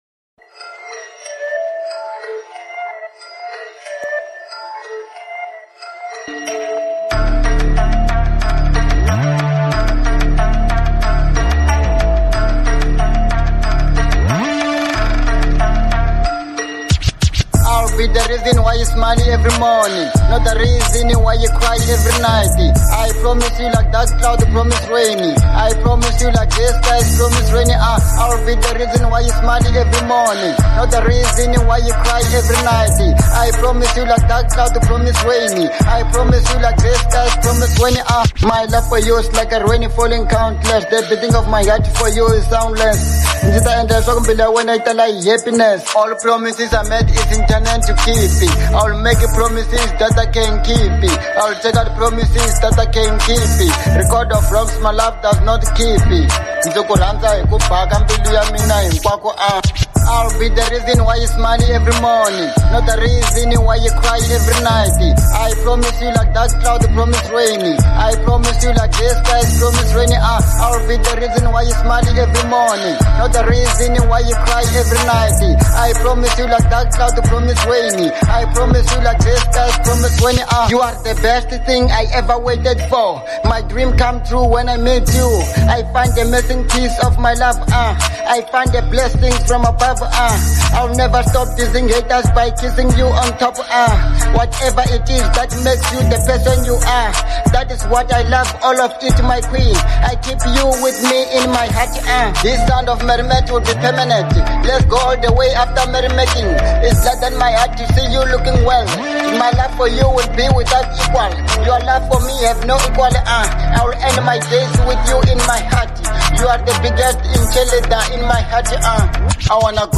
02:53 Genre : Hip Hop Size